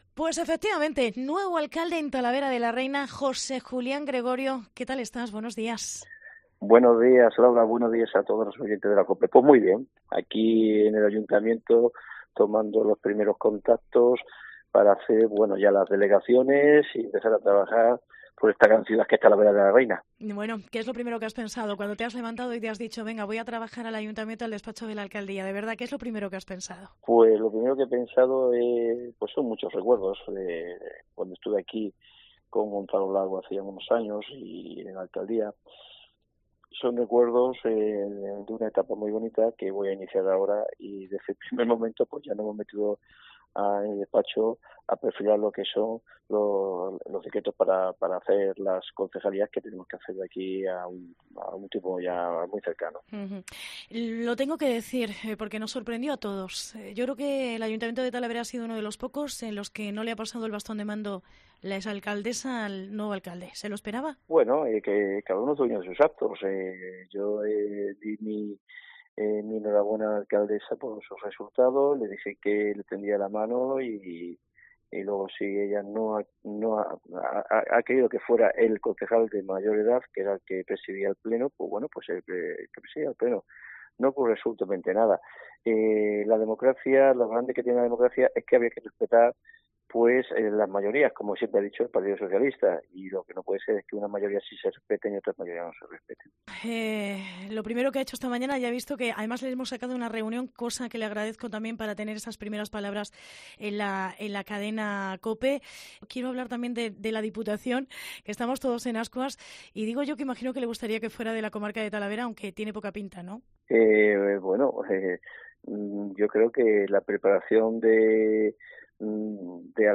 Entrevista con José Julián Gregorio en las primeras horas como alcalde de Talavera de la Reina